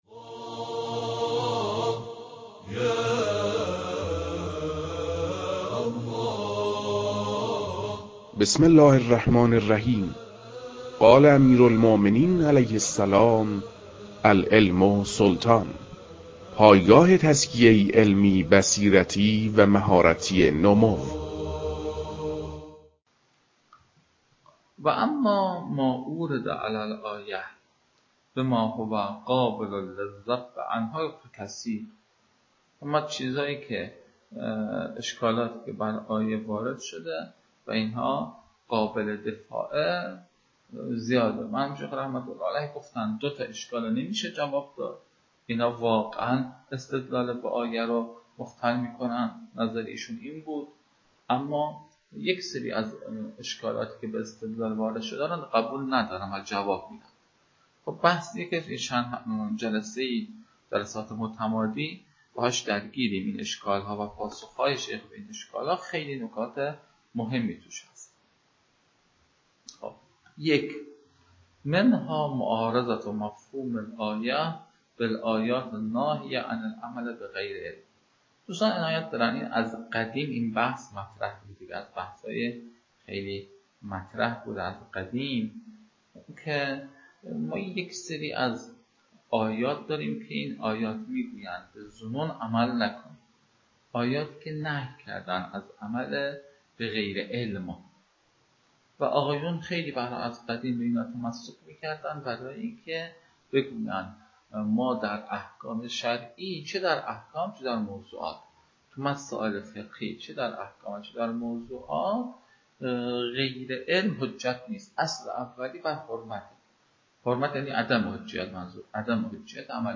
لازم به‌ذکر است که این فایل، از محتوای دو جلسه تدریس ترکیب یافته است.